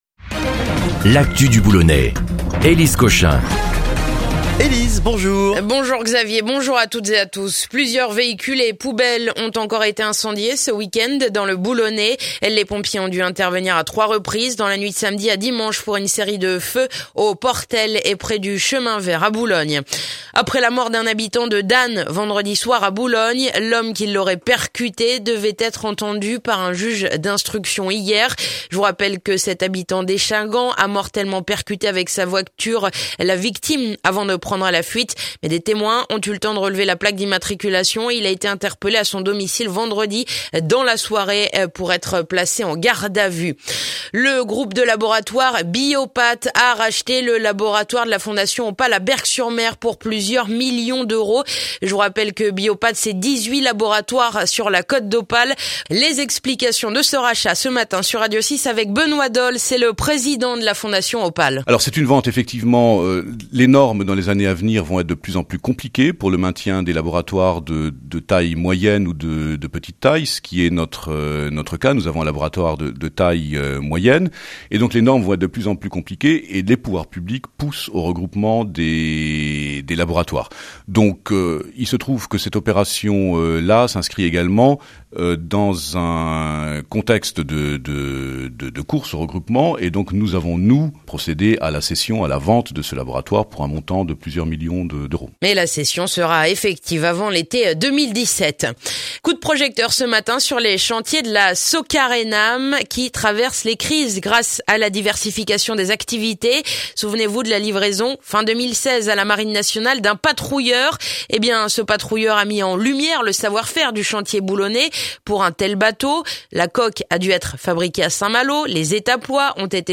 Le journal du lundi 20 février dans le boulonnais